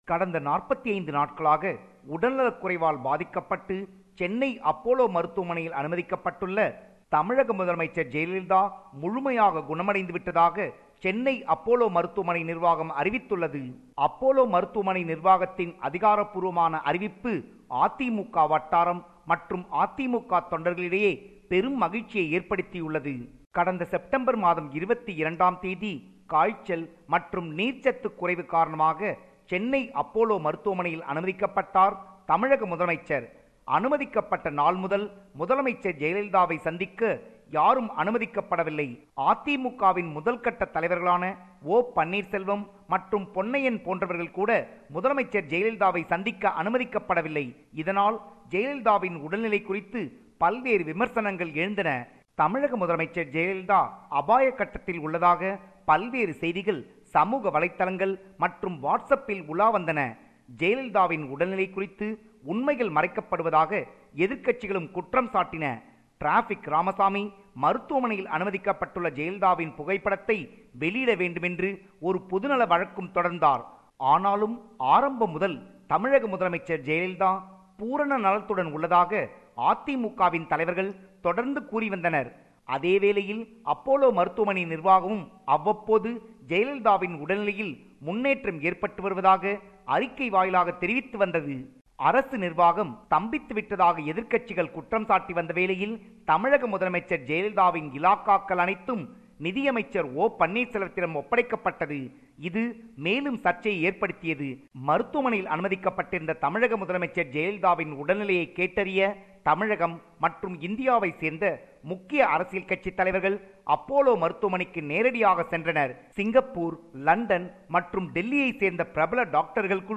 compiled this report